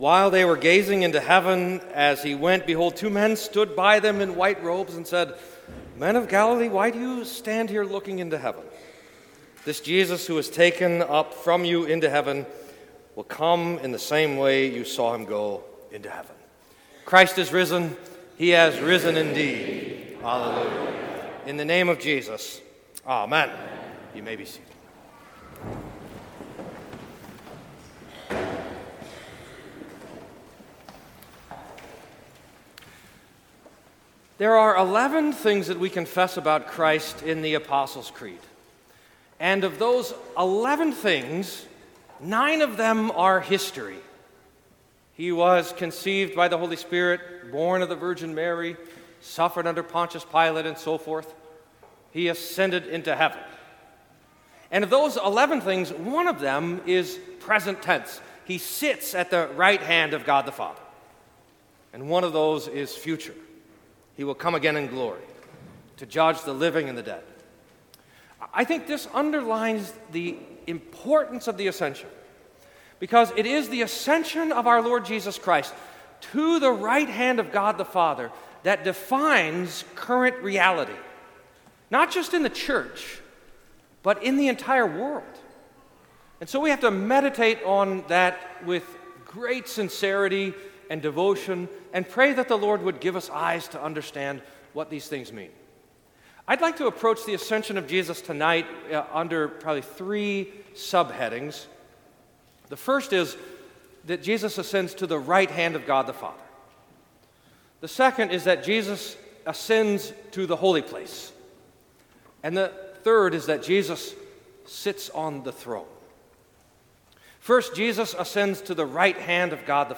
Sermon for the Ascension of Our Lord